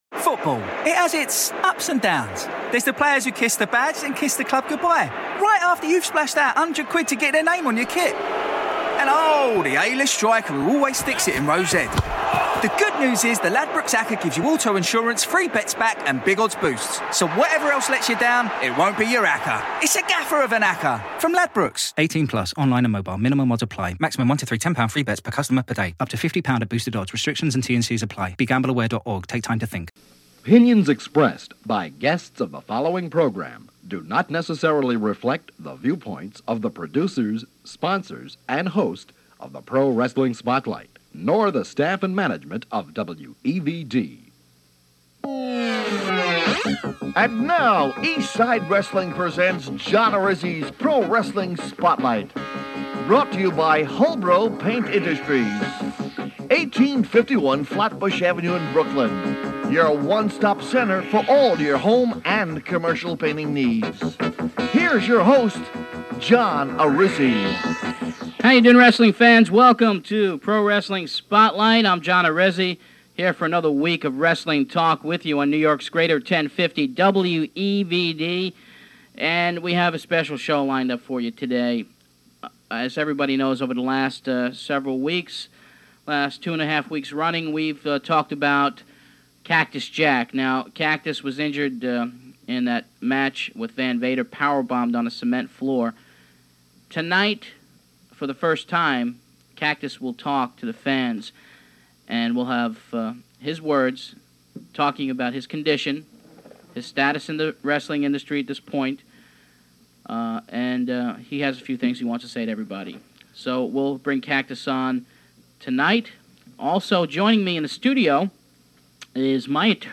On this episode we share the May 8th, 1993 broadcast of Pro Wrestling Spotlight, which aired on 1050 AM WEVD in New York City, NY. On this episode we are joined by Cactus Jack calling in from his home, where he is laid up from the injuries sustained when Vader power bombed him on the cement at a WCW TV taping.